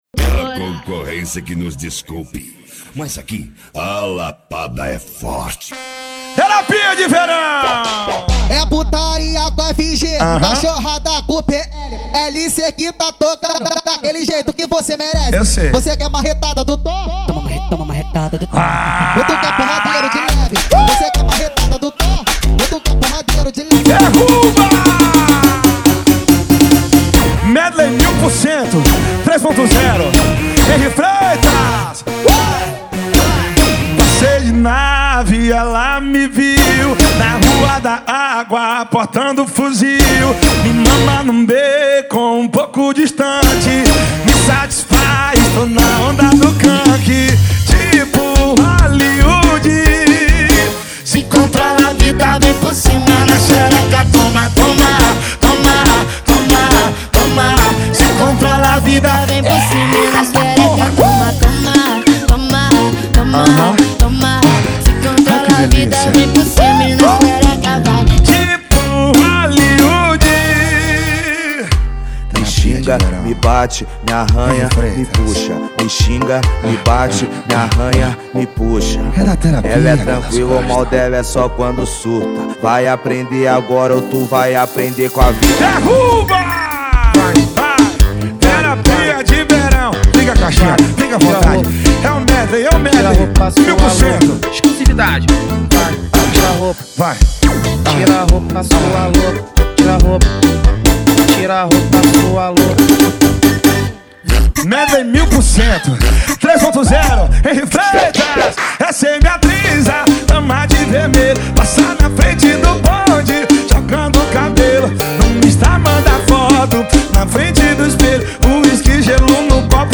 2024-02-14 18:21:08 Gênero: Forró Views